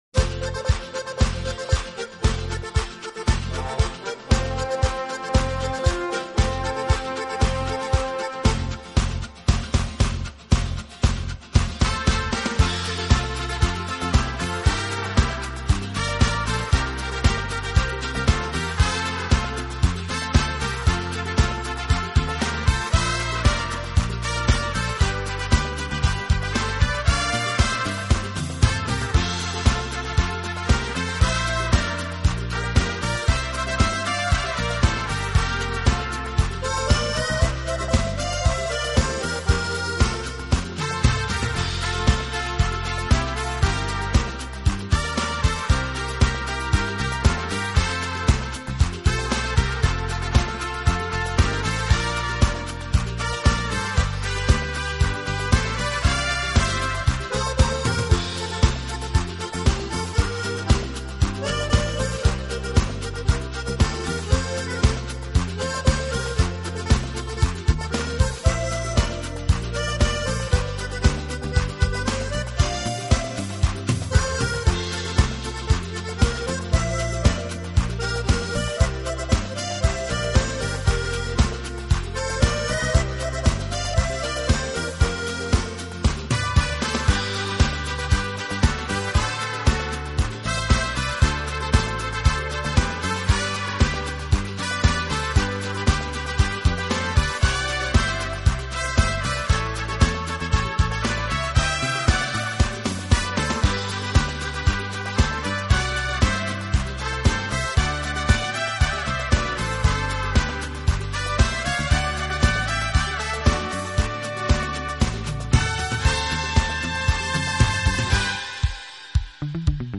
顶级轻音乐